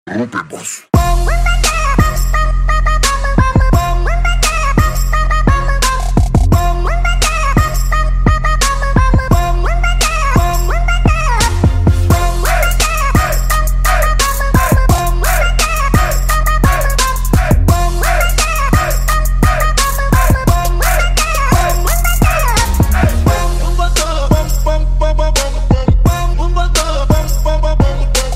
• Качество: Хорошее
играет Trap рингтоны🎙